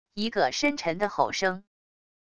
一个深沉的吼声wav音频